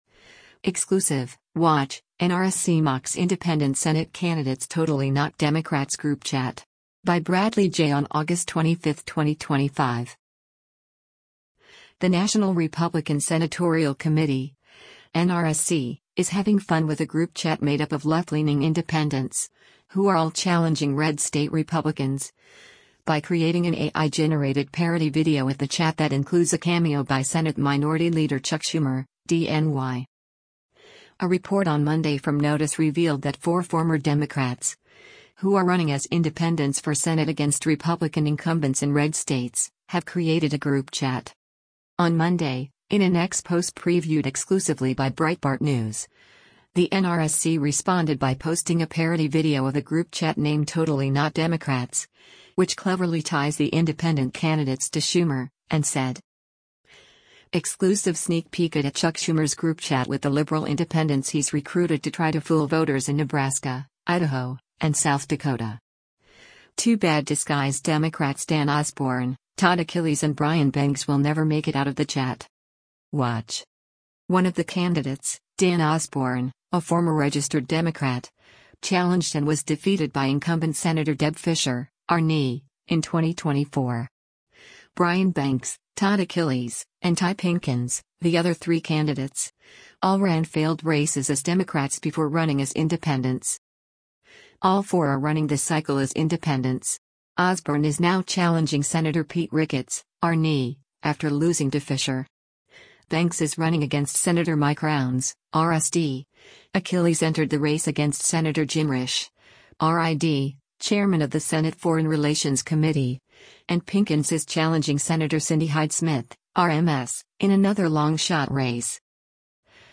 The National Republican Senatorial Committee (NRSC) is having fun with a group chat made up of left-leaning “independents,” who are all challenging red-state Republicans, by creating an AI-generated parody video of the chat that includes a cameo by Senate Minority Leader Chuck Schumer (D-NY).